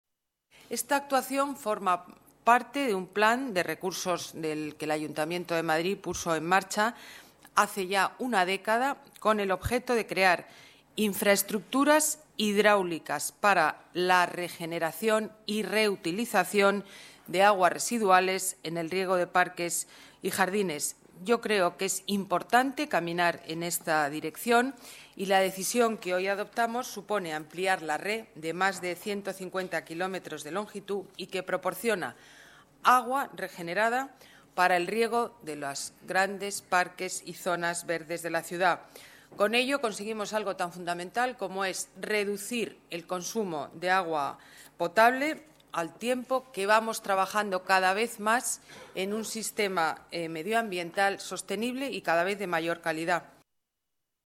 Nueva ventana:Declaraciones alcaldesa Madrid, Ana Botella: Junta de Gobierno, agua regenerada para el Parque Juan Pablo II